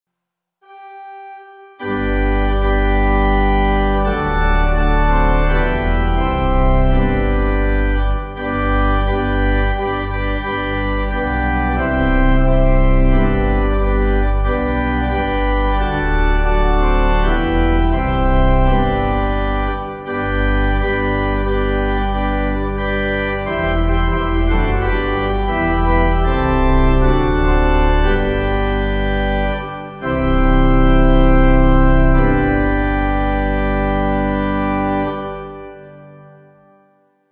With Amen